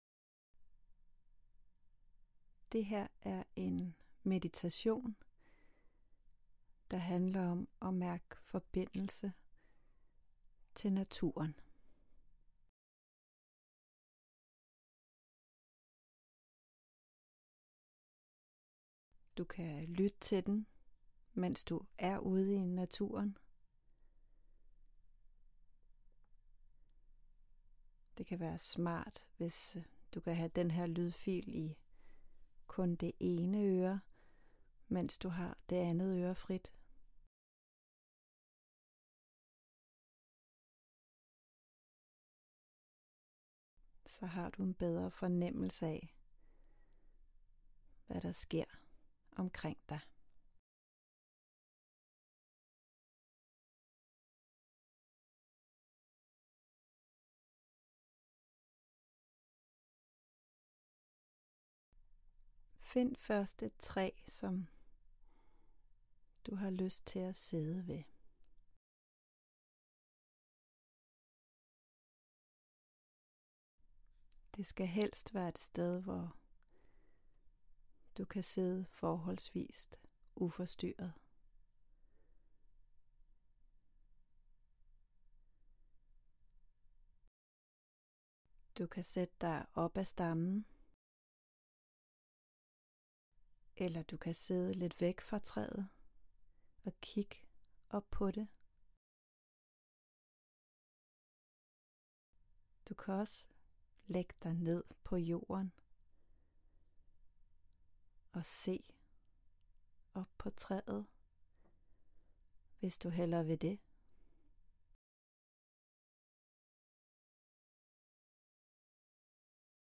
Bliv guidet til meditations- og afspændingsøvelser.